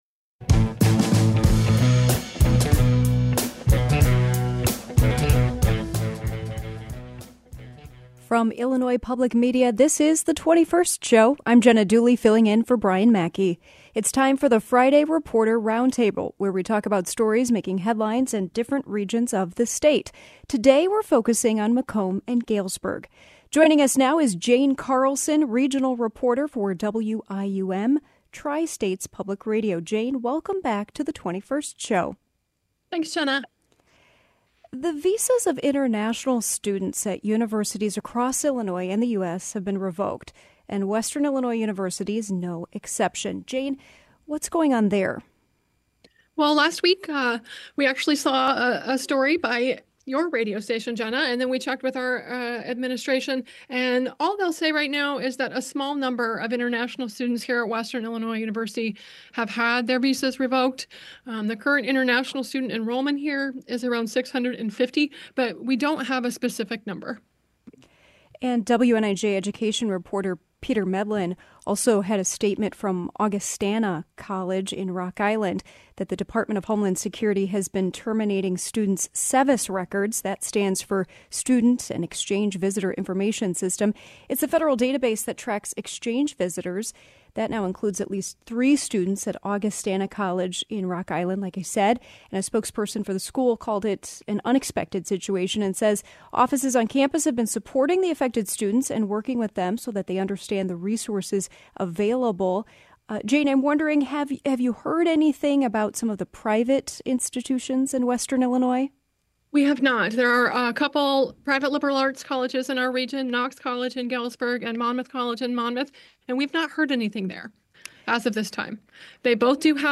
In the April 18, 2025 state reporter roundup, we checked in with Macomb and Galesburg where the Trump administration continues to revoke visas of international students at Illinois universities. Also, legal Haitian immigrants are being deported. Plus, an economic resurgence happening in Macomb.